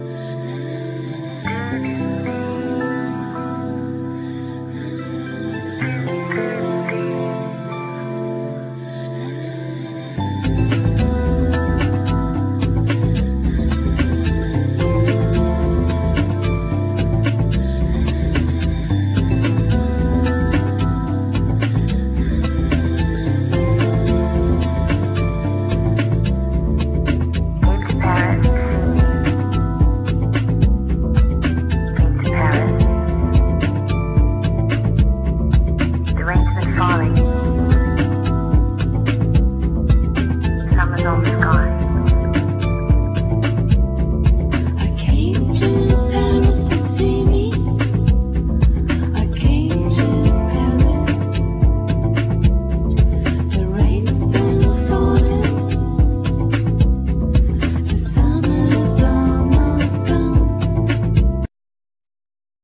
Trumpet
Turntables
Drums
Bass
Fender piano
Synthsizer, Hammond organ
African percussions
Guitar